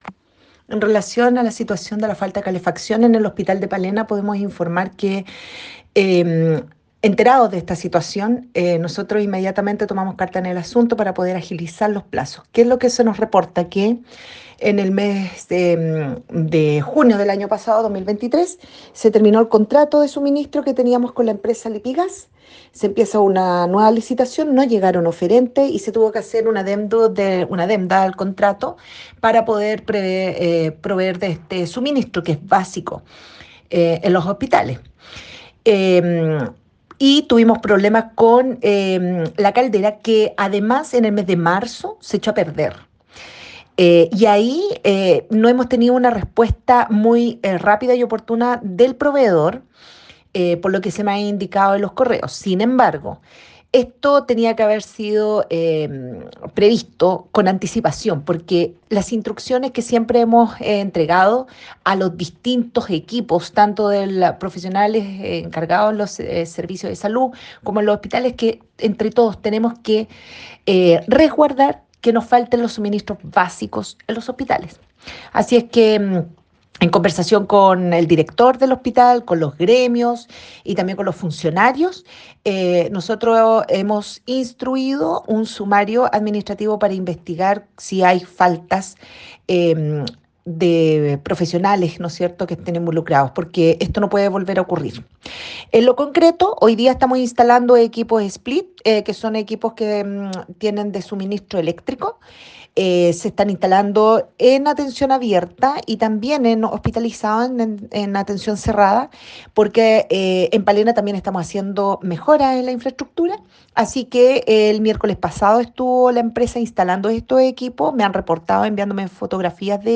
La Directora del Servicio de Salud Reloncaví, Bárbara del Pino, se refirió a las medidas para solucionar este problema, agregando que además se instruyó un sumario administrativo.